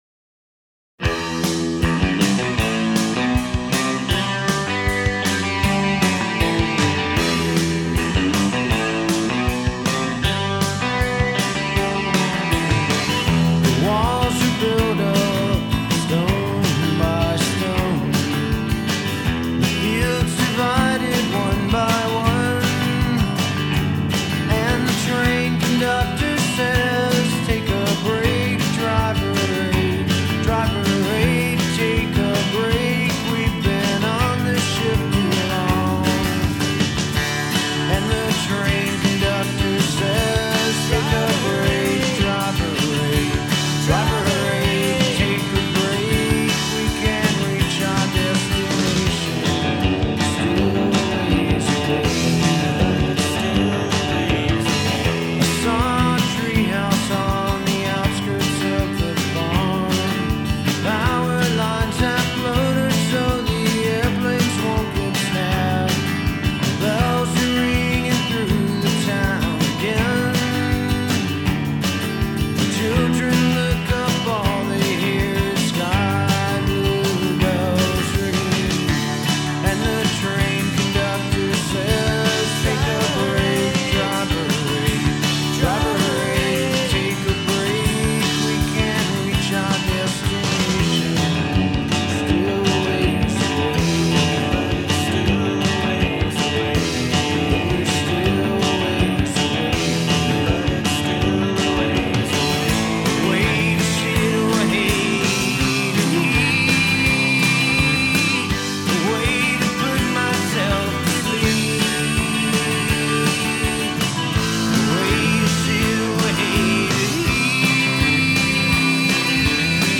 Heady stuff, but it also rocks pretty damn good.